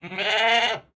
sounds / mob / goat / pre_ram1.ogg